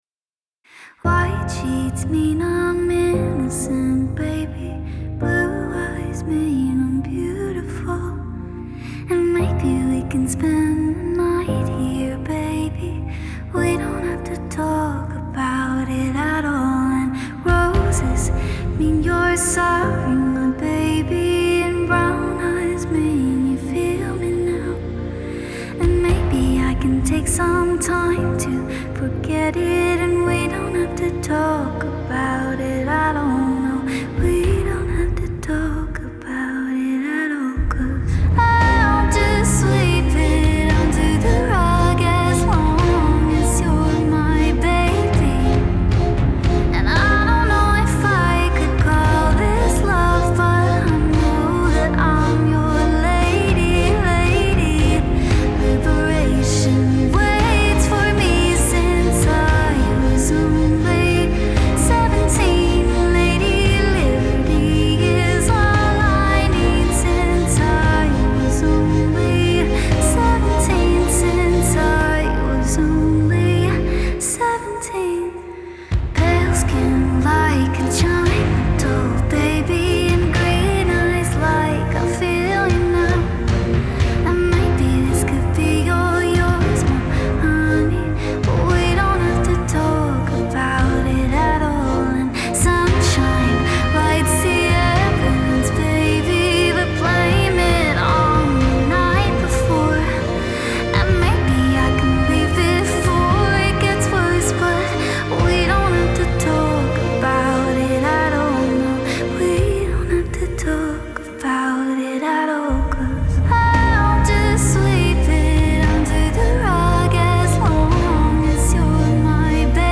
Genre: Electronic, Pop